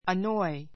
annoy A2 ənɔ́i ア ノ イ 動詞 （一時的に） いらいらさせる, いやがらせる, 困らせる; be annoyed で いらいらする, 困る The noise from the street annoys me.